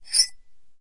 玻璃 陶瓷 " 陶瓷冰激凌碗用金属勺子刮削 02
描述：用金属勺刮一个陶瓷冰淇淋碗。 用Tascam DR40录制。
Tag: 金属勺 金属 陶瓷